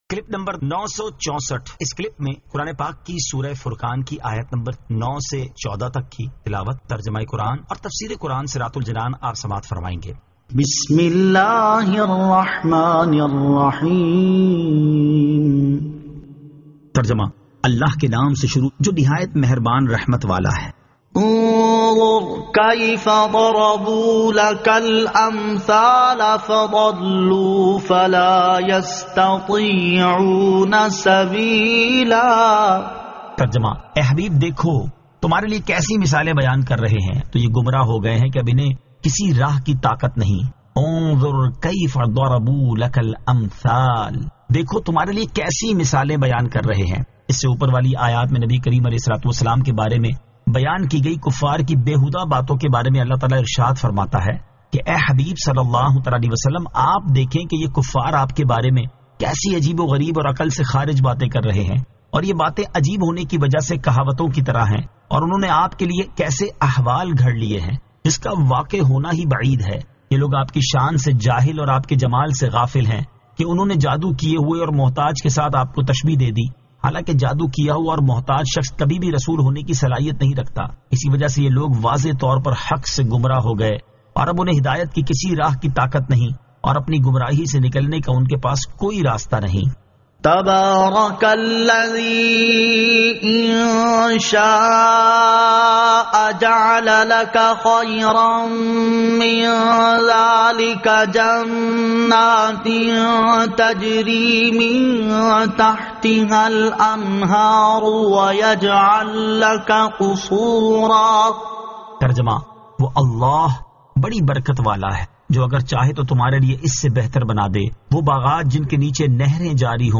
Surah Al-Furqan 09 To 14 Tilawat , Tarjama , Tafseer